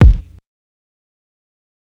Kick (13).wav